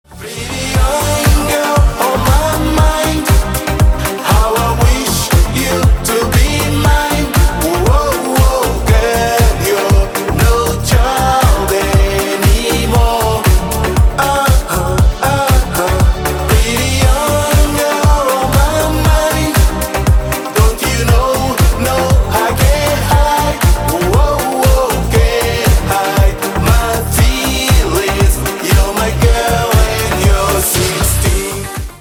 • Качество: Хорошее